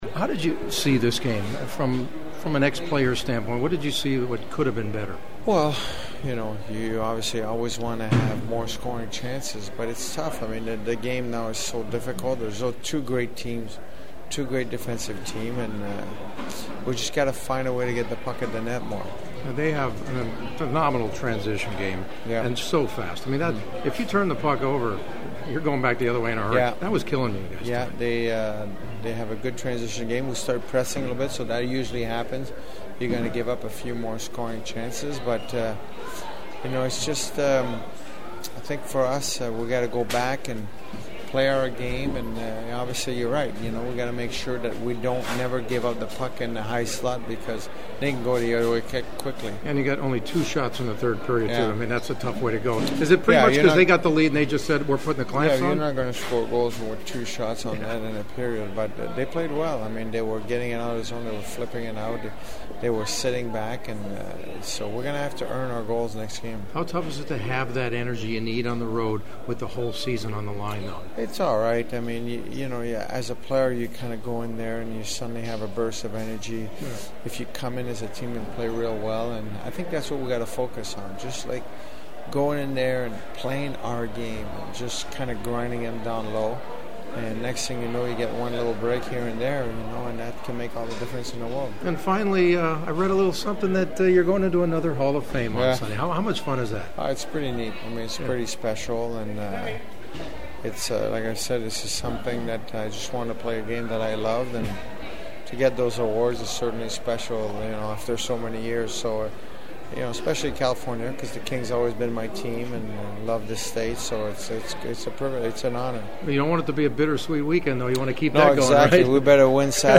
The following are my sounds of the postgame and this one was far from fun to get the losing side to have to comment on this game and what lies ahead…which immediately is a potential season-ending game 5 in Chicago on Saturday.
Kings VP Luc Robitaille (who’s also to be inducted into the California Sports Hall of Fame on Sunday):